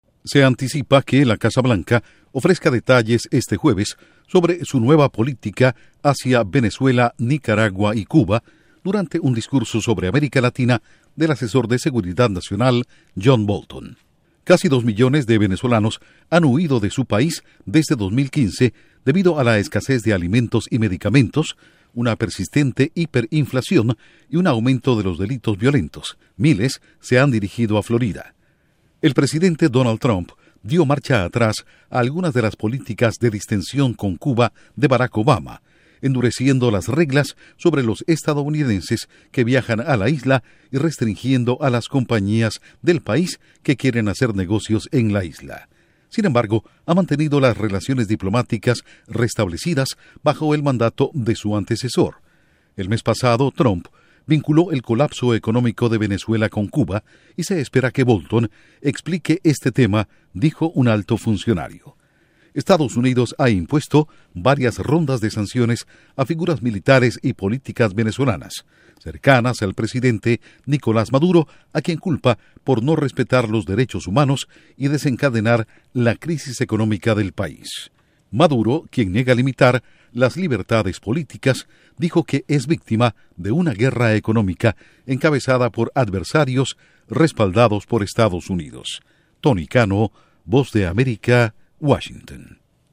Asesor de Trump prepara informe sobre nueva política de Estados Unidos hacia Venezuela, Nicaragua y Cuba. Informa desde la Voz de América en Washington